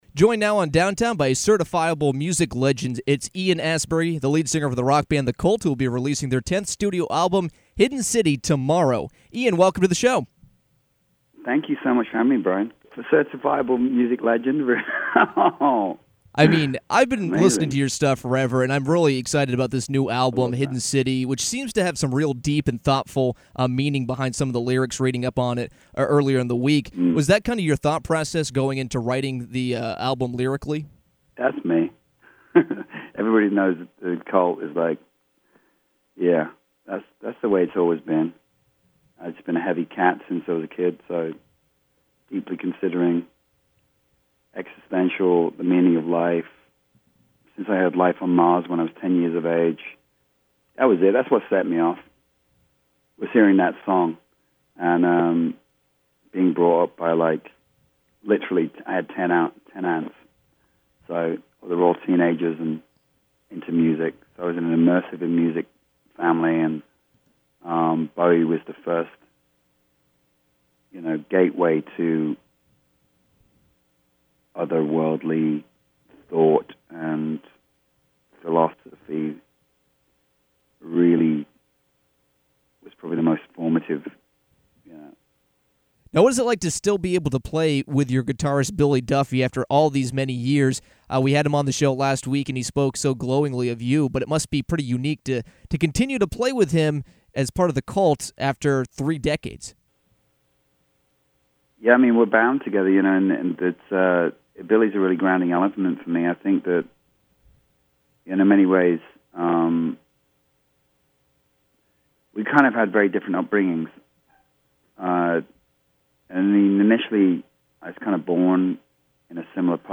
Ian Astbury, the lead singer of the band The Cult, joined Downtown to talk about the debut of their 10th studio album “Hidden City” which will be on sale tomorrow.